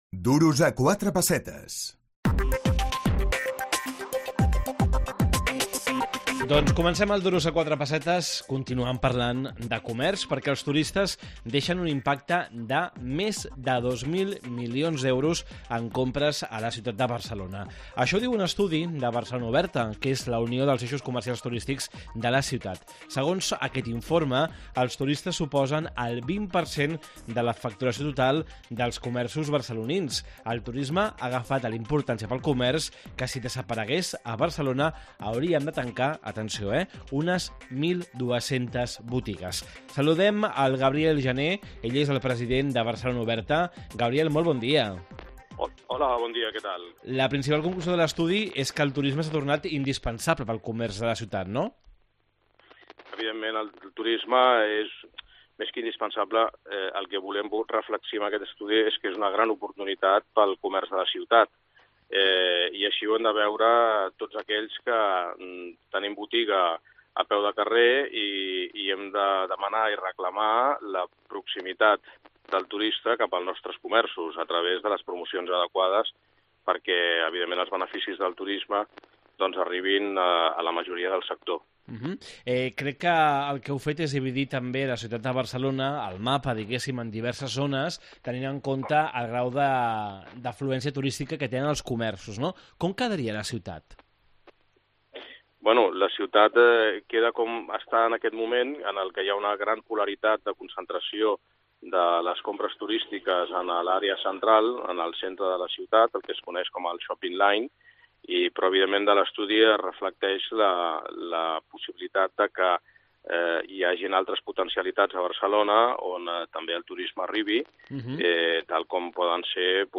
AUDIO: Els turistes es deixen més de 2.000 milions d’euros en compres a Barcelona. Entrevista